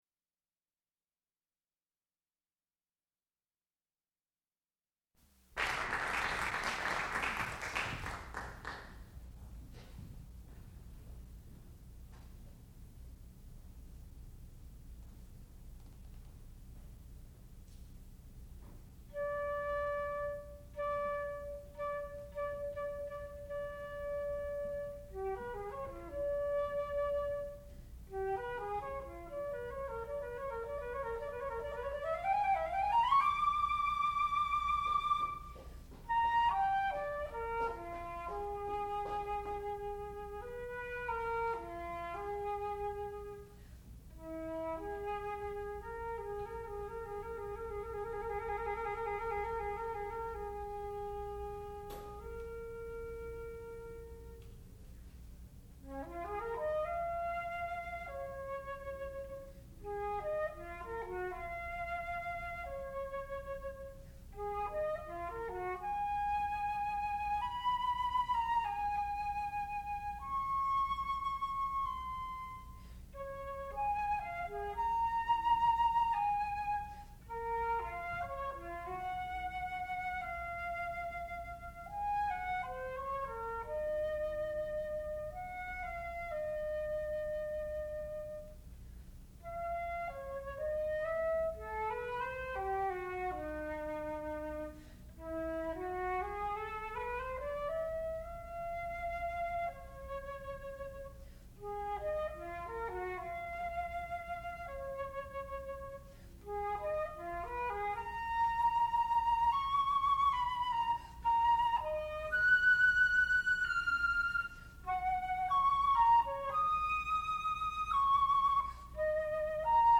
sound recording-musical
classical music
Master's Recital